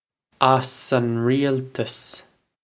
Pronunciation for how to say
os on ree-ultus
This is an approximate phonetic pronunciation of the phrase.